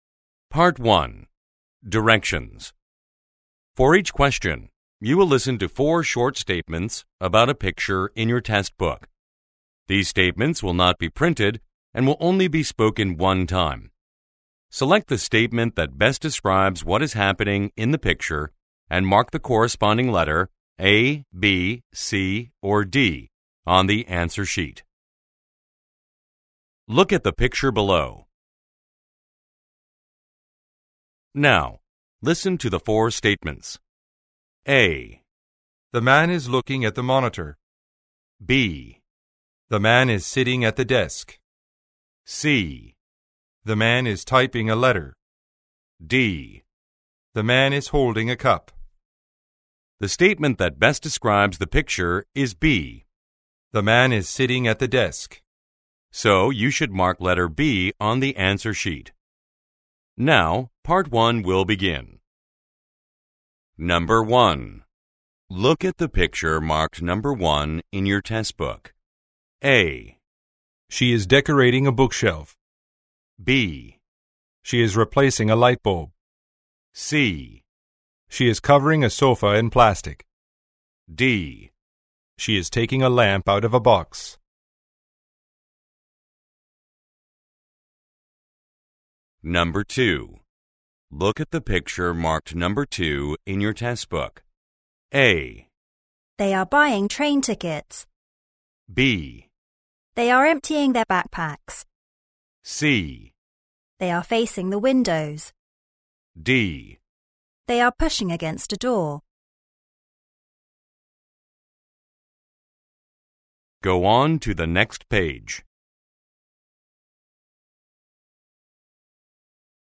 除了按照實際測驗內容，以美、英、澳、加四國口音錄製的完整模擬測驗音檔，本書按照使用情境，製作了 3 種剪接版本的音檔：
本書以英、美、加、澳四國口音來錄製完整的模擬測驗錄音檔，幫助你熟悉多種發音、掌握關鍵訊息不漏聽。